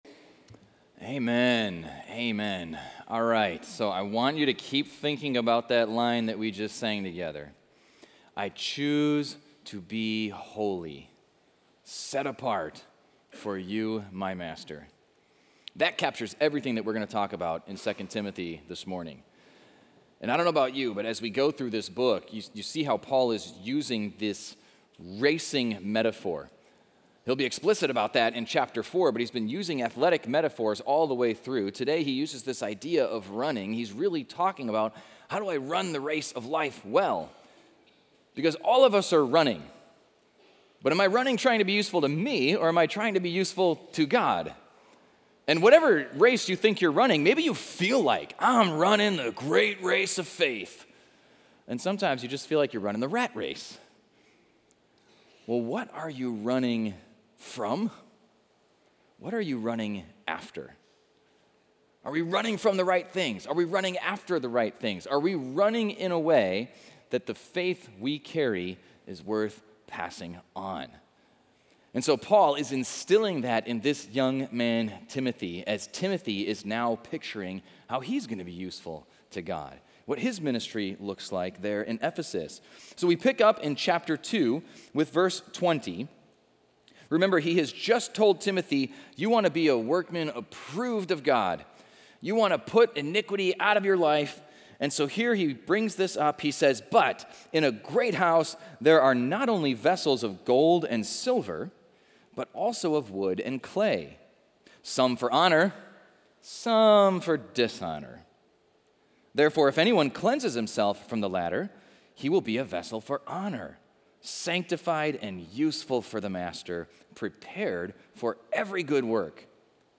Equipping Service / 2 Timothy: Pass It On / Be Useful for the Master